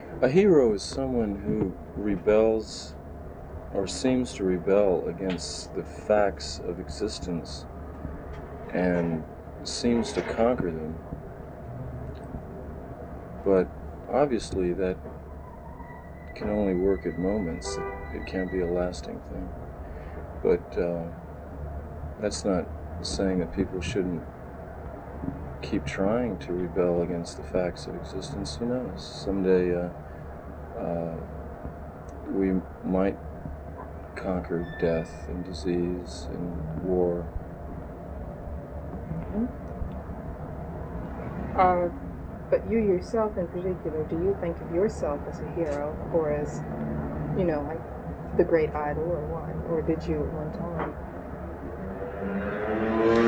15 Jim A Hero Is Someone Who Rebels Against the Facts of Existence (The Lost Interview Tapes - Volume Two).flac